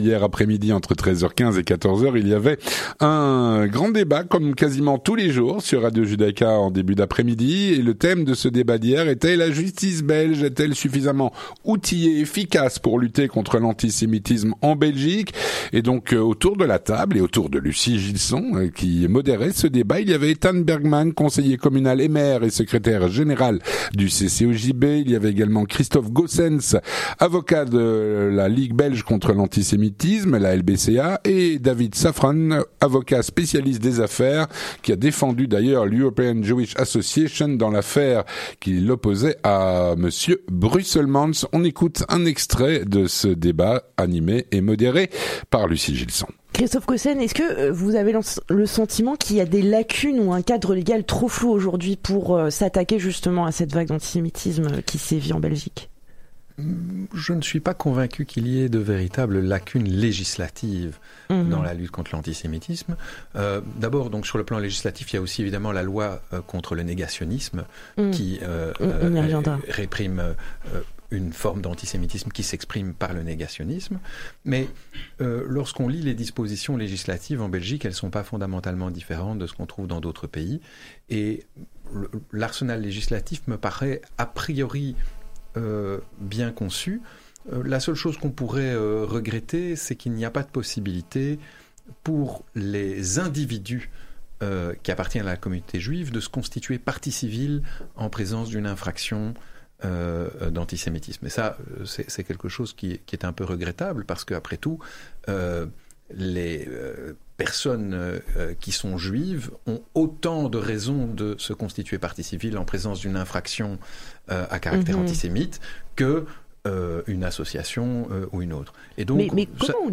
Le Grand Débat - La Justice belge est elle suffisamment efficace pour lutter contre l’antisémitisme en Belgique ?